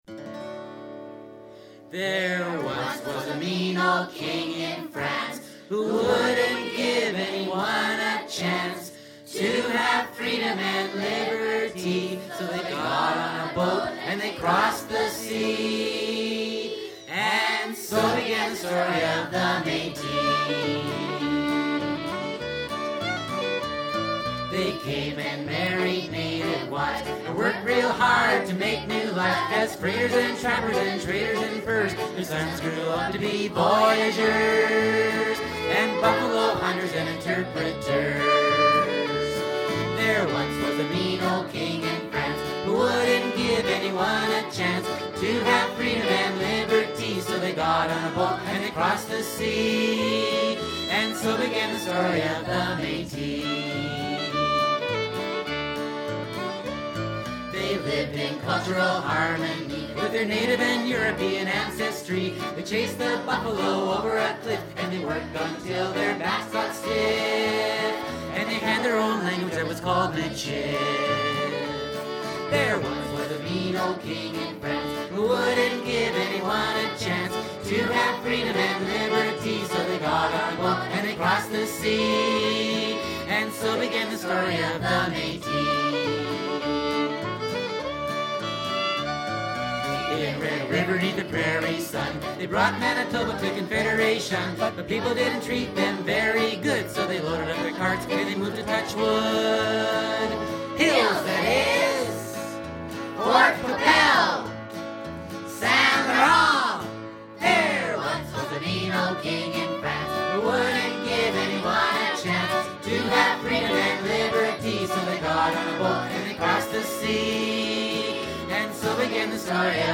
Lots of traditional Métis type fiddle music.